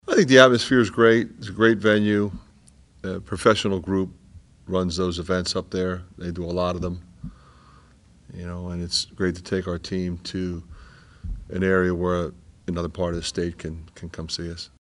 That’s Iowa coach Fran McCaffery.